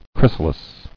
[chrys·a·lis]